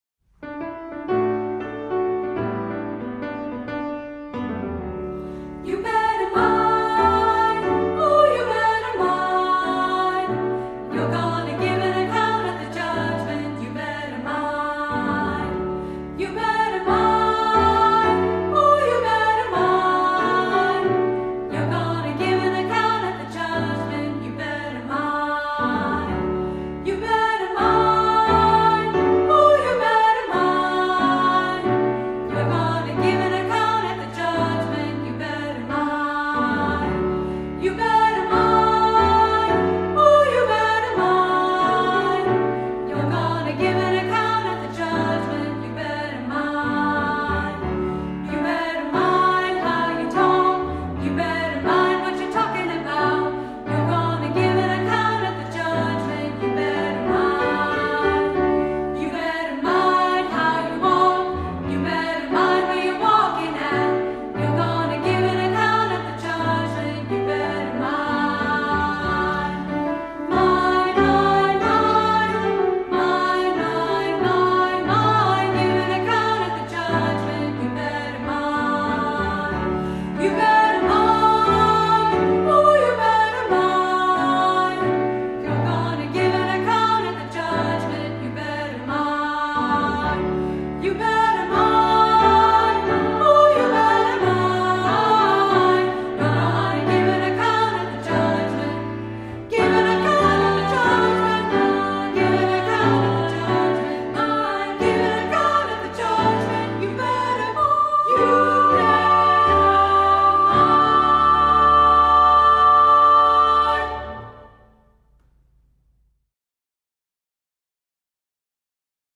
Voicing: Two-part children's choir - SA